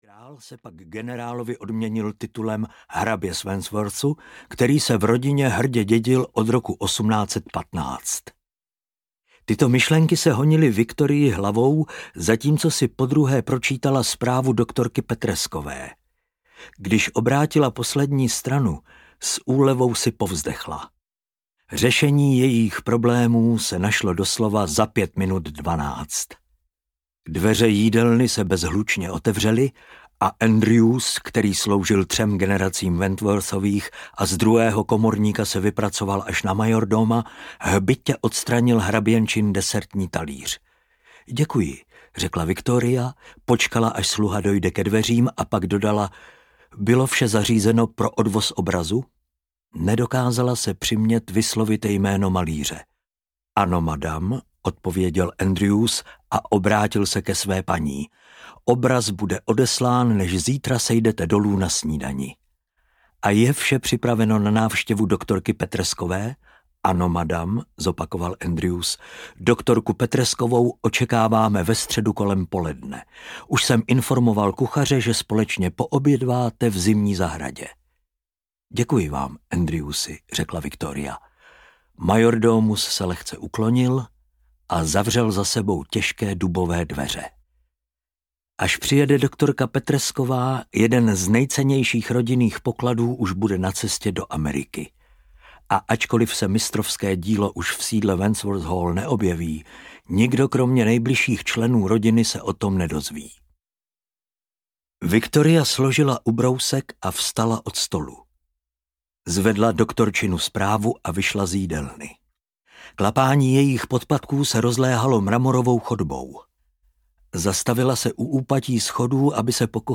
Klamný dojem audiokniha
Ukázka z knihy